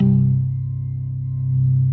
B3LESLIE C 2.wav